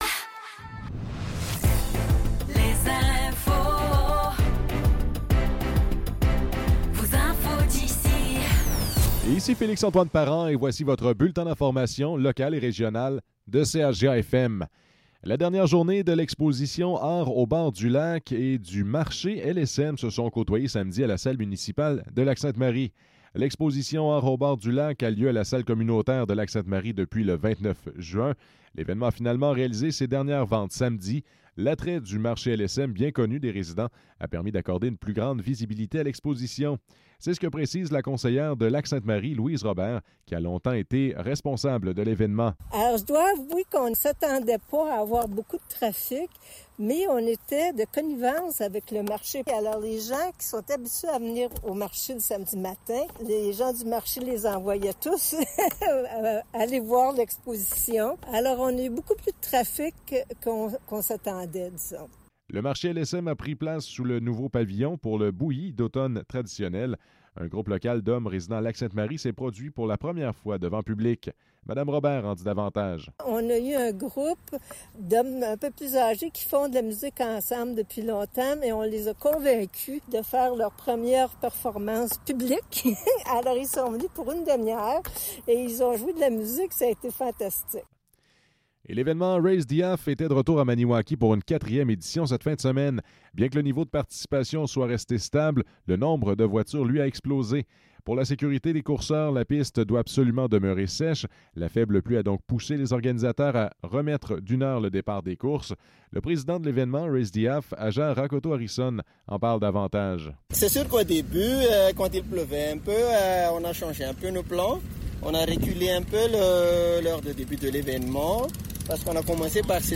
Nouvelles locales - 3 septembre 2024 - 12 h